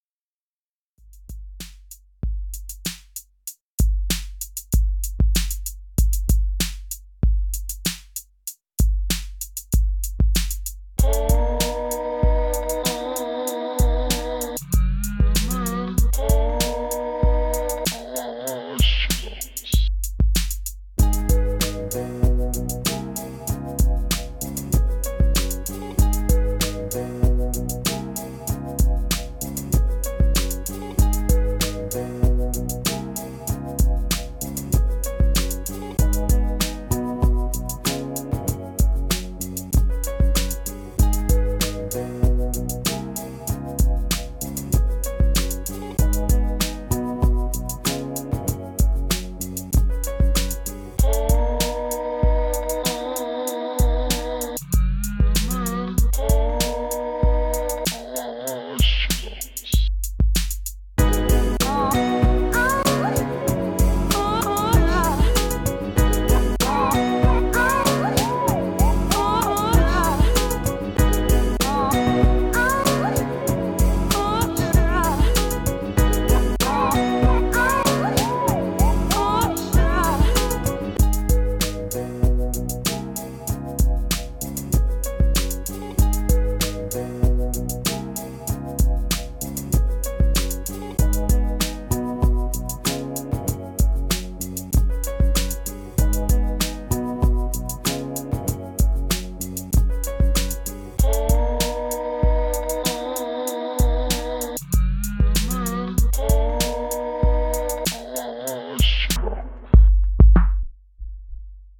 It’s reminiscent of 90s Bay Area hip hop.